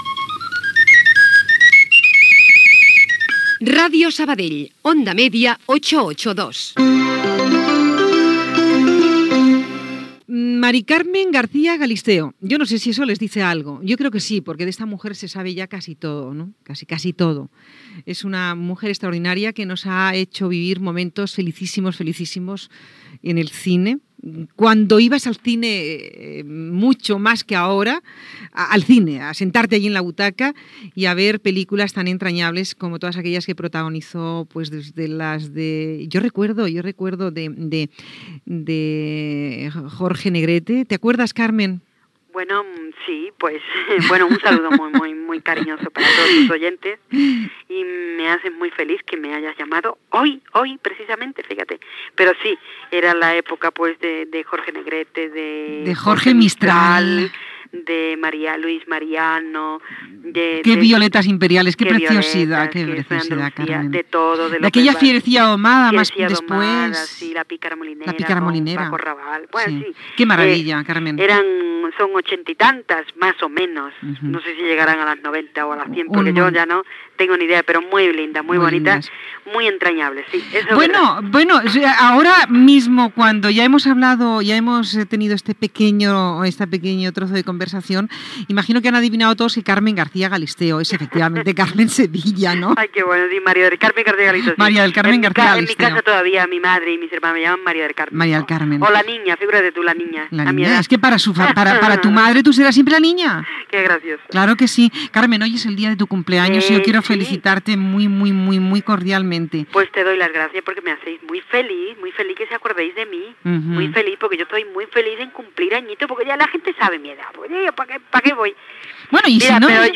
Indicatiu de l'emissora, entrevista a l'actriu Carmen Sevilla (María del Carmen García Galisteo) en el dia del seu aniversari. Shi parla de la seva joventut, de la seva família i del treball a Telecinco